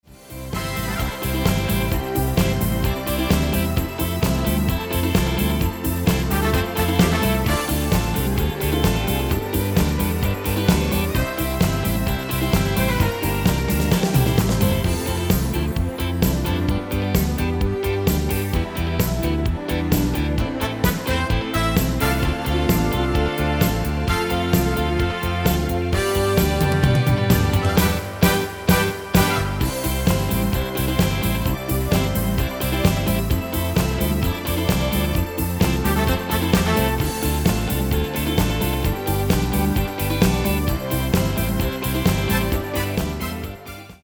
Demo/Koop midifile
Genre: Nederlandse artiesten pop / rock
Toonsoort: C/C#